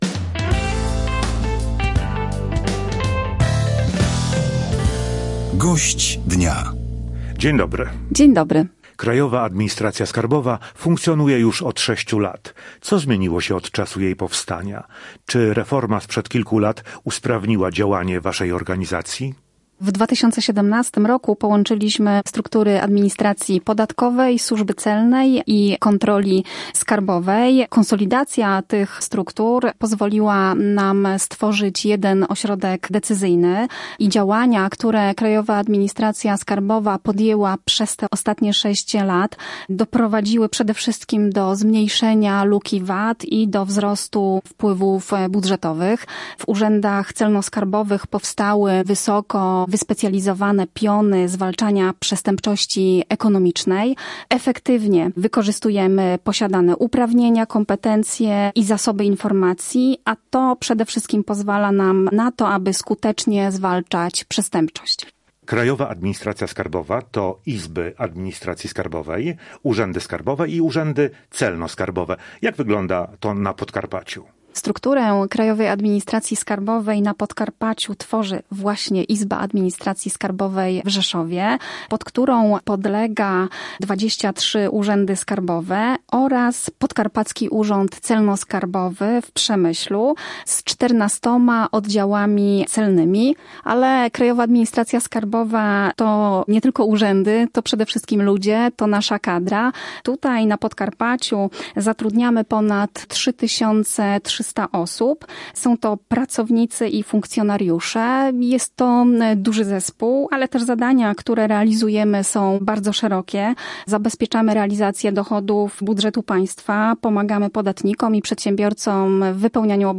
Dzień Krajowej Administracji Skarbowej. Rozmowa